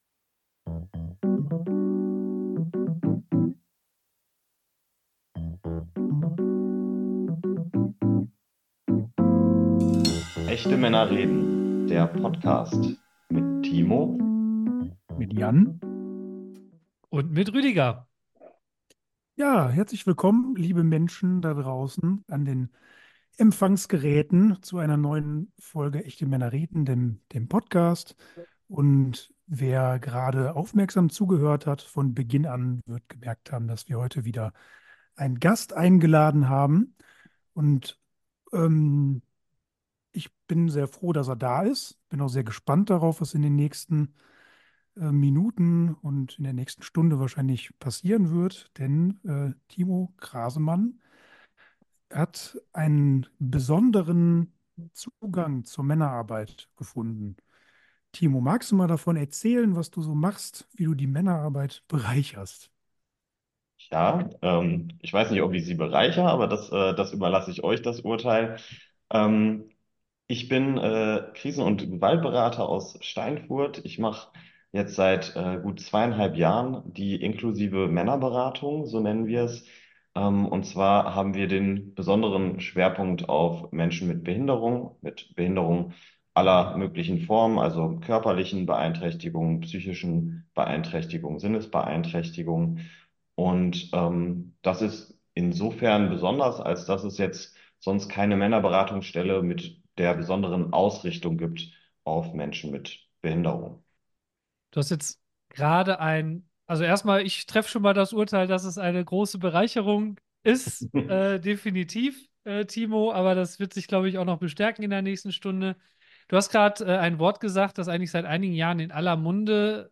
Schnell wird im Gespräch deutlich, dass es beim Konzept nicht um eine Randgruppe, sondern tatsächlich um uns alle geht. Und dass wir sehr viel von Menschen mit Behinderung lernen können.